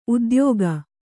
♪ udyōga